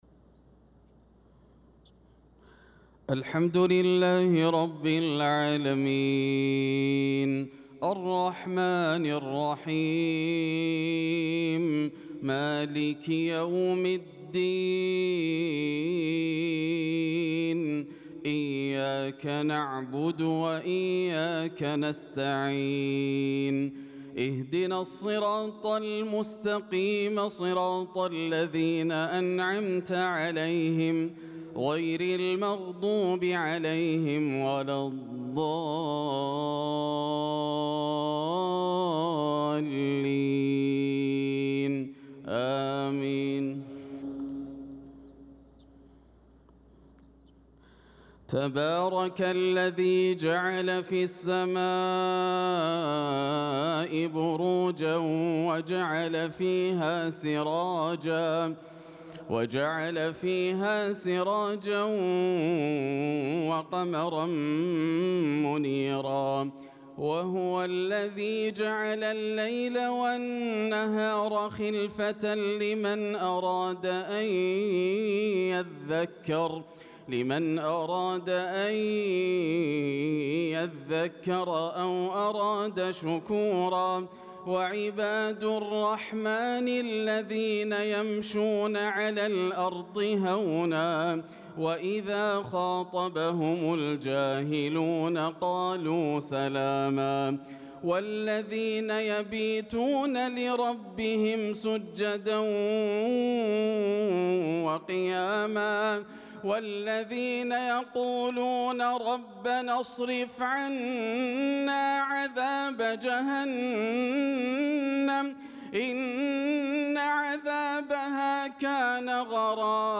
صلاة العشاء للقارئ ياسر الدوسري 26 ذو القعدة 1445 هـ
تِلَاوَات الْحَرَمَيْن .